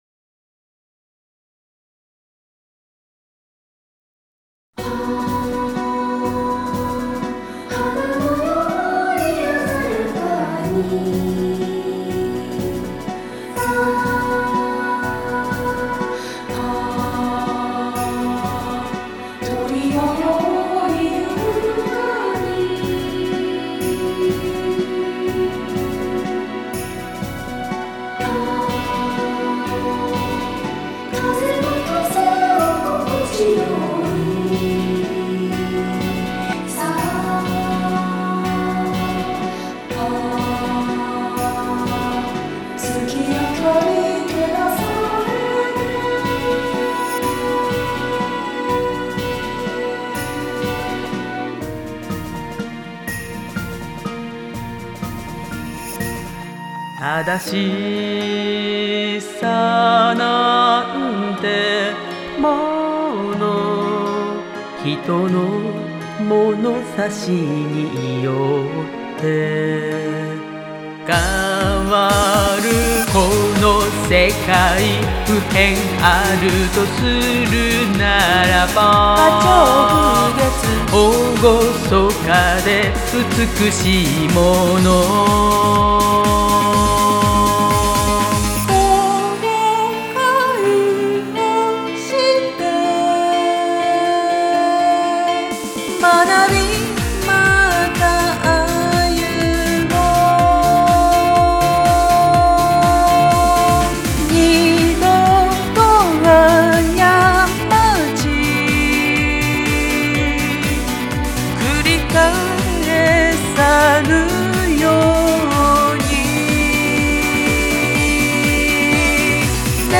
…で、開いて聴いてみたら、バランスが激烈に悪いｗｗｗ
てことで、現環境でそこそこ聴けるかな？　程度の調整をしてみました。
９～8年前の声なので、自分の声じゃないみたい。
混声4声の重唱としてアレンジしたんですが、微妙にカルテットとは言い難い感じの仕上がりです。
【ひとりで4人編成】